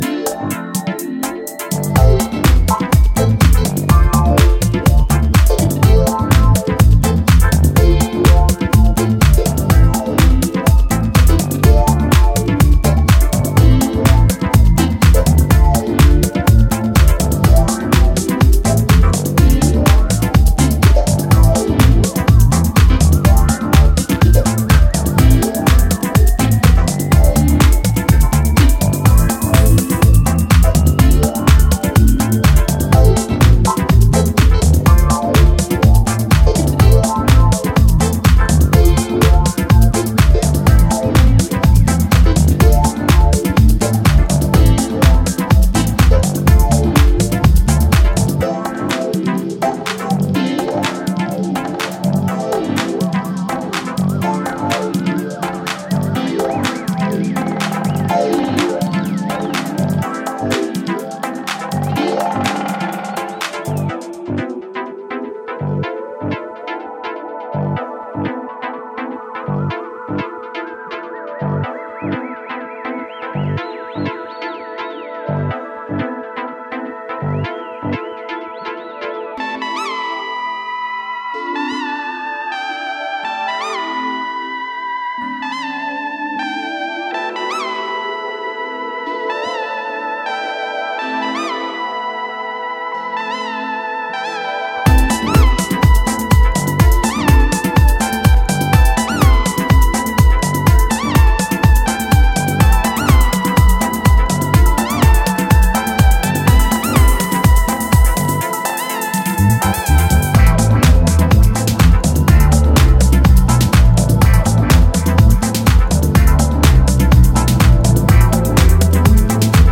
is more soulful house with a breezy synth vibe